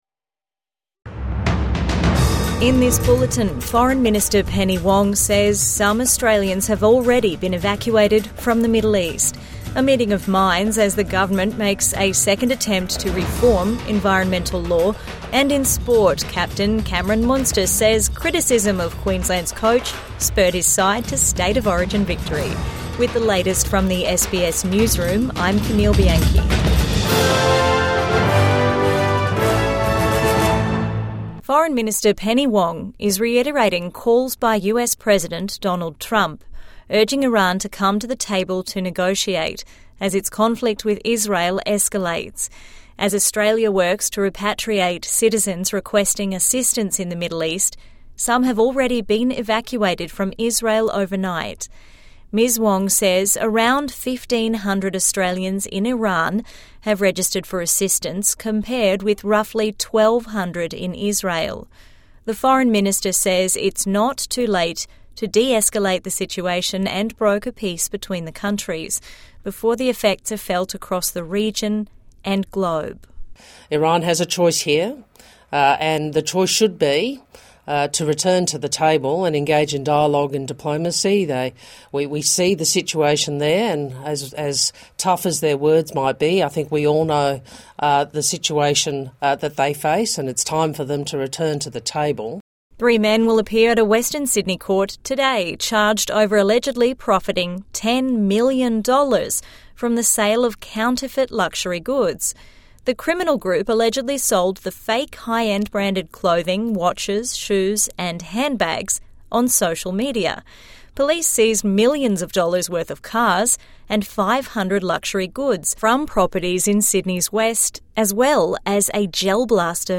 Australians being evacuated from Middle East | Midday News Bulletin 19 June 2025